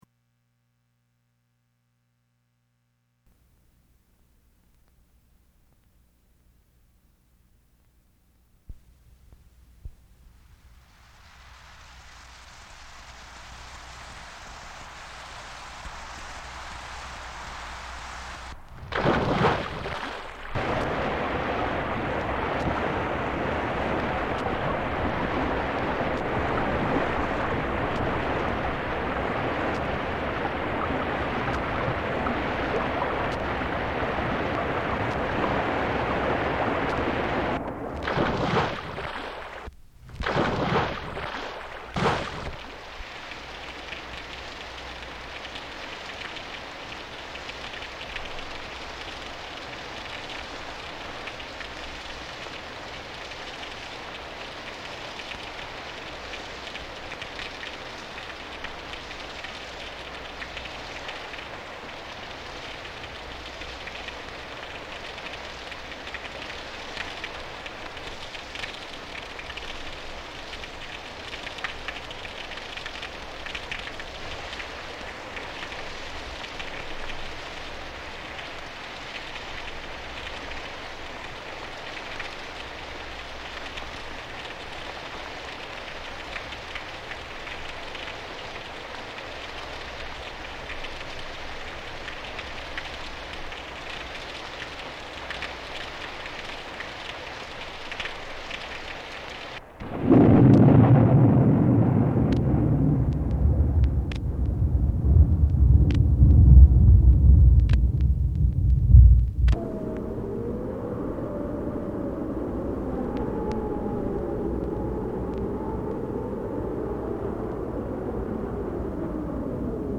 Blue Moon (1997) cassette soundtrack to my first film mp3